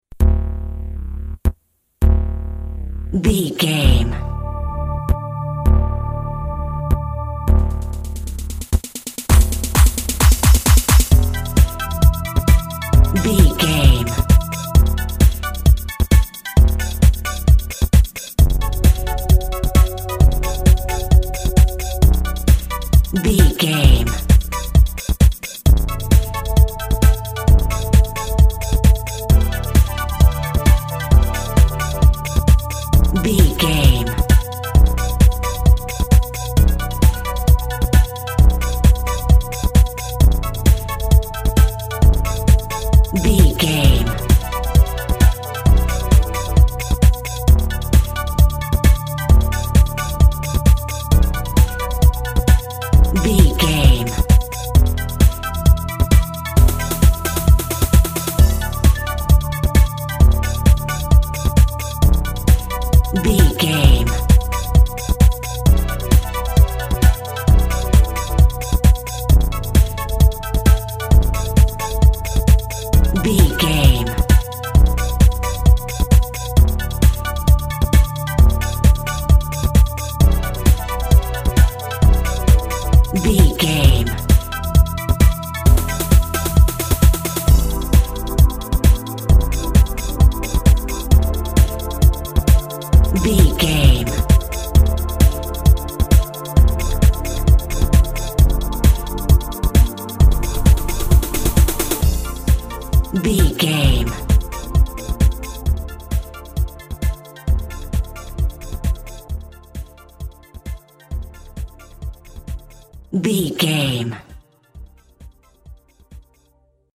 Cheesy Electronic Music Cue.
Aeolian/Minor
D
groovy
futuristic
energetic
cheerful/happy
funky
drum machine
techno
synth lead
synth bass
electronic drums
Synth Pads